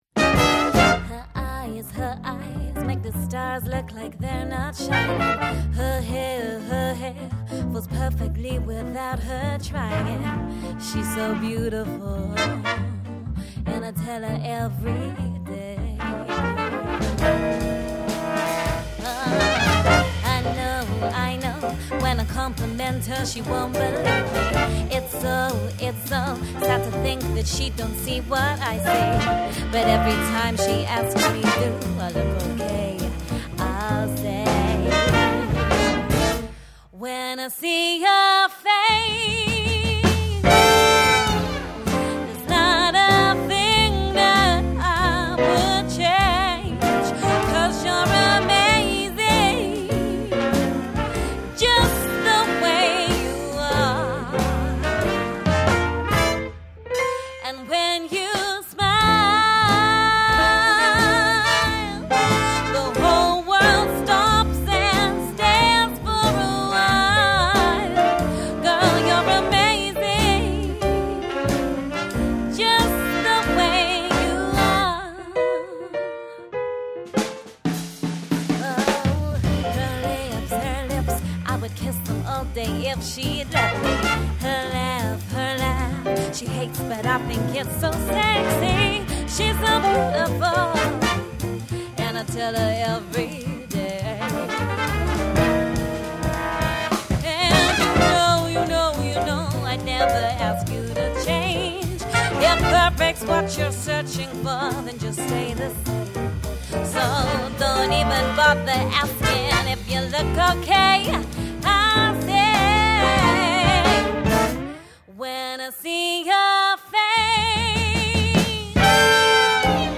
unique vintage-style arrangements
• Jazz/Pop Crossover
Vocals, Bass, Drums, Keys, Trumpet, Sax, Guitar, Trombone